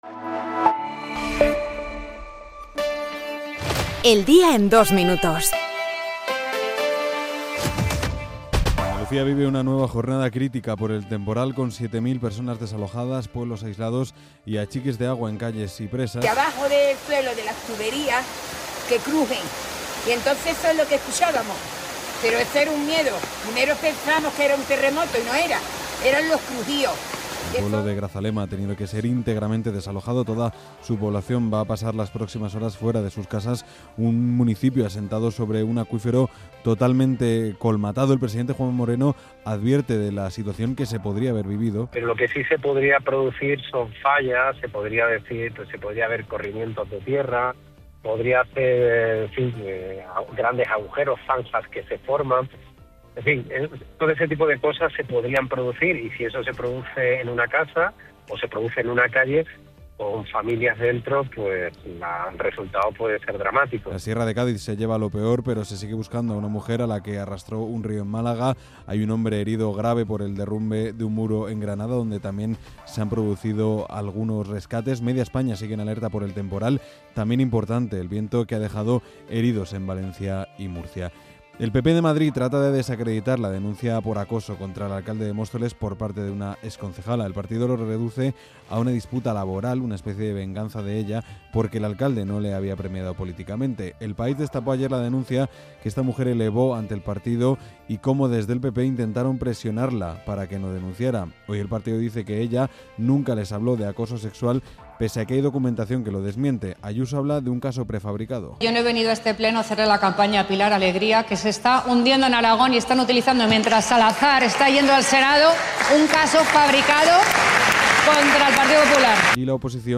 El resumen de las noticias de hoy